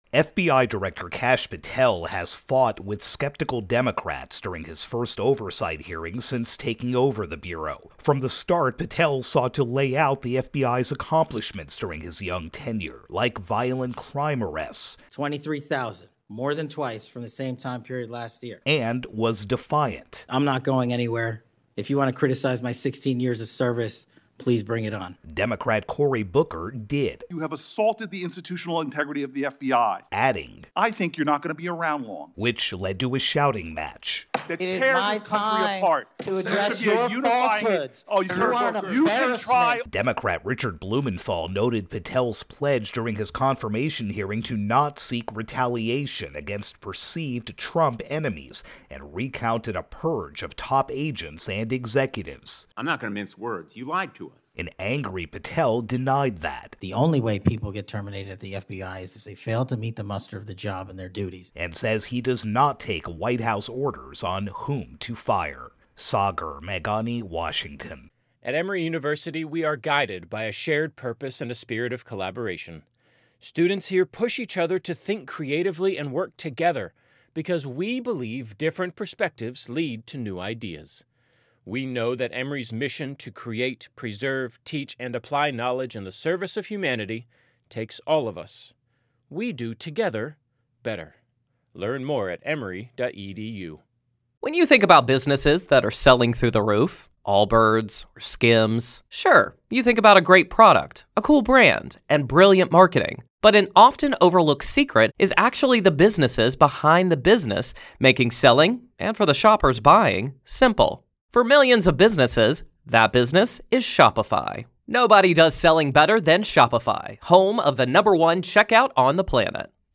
reports on FBI Director Kash Patel's testy hearing before a Senate panel.